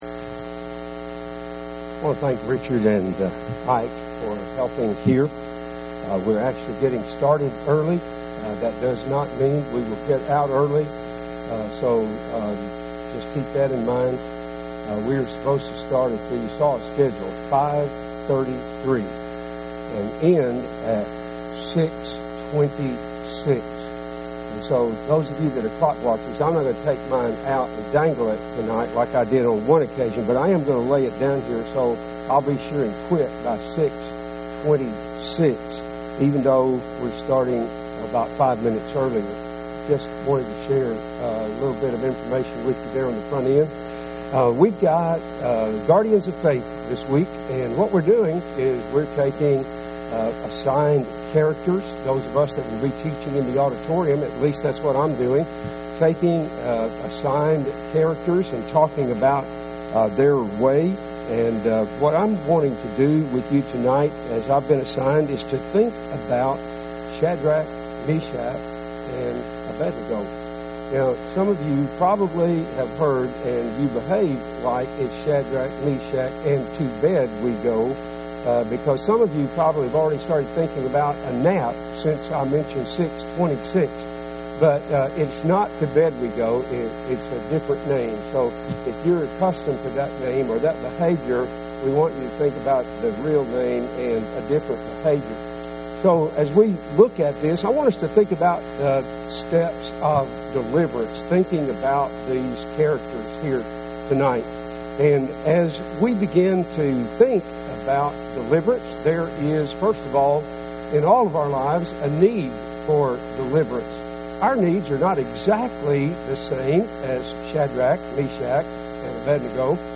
VBS adult class in auditorium.